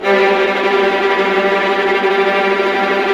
Index of /90_sSampleCDs/Roland LCDP08 Symphony Orchestra/STR_Vas Bow FX/STR_Vas Tremolo